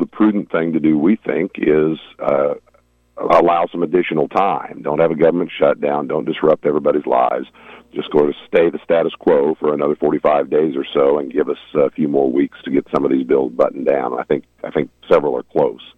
He had addressed the possibility during his monthly visit on KVOE’s Morning Show late last week.